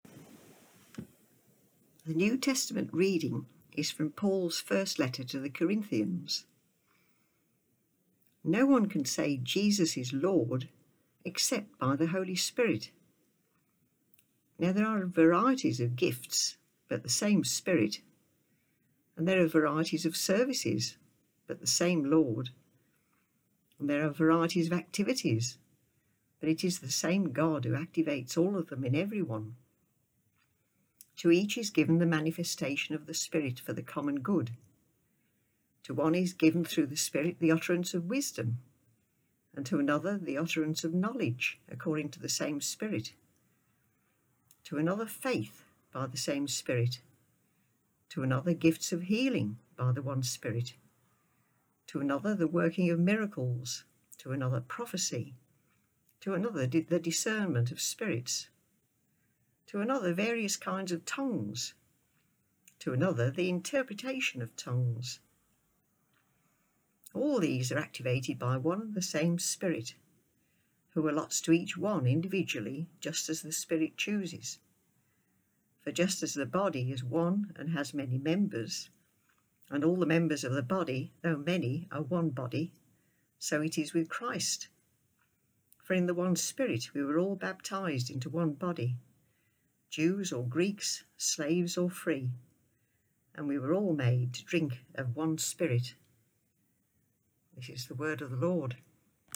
SECOND READING 1 Corinthians 12.3b–13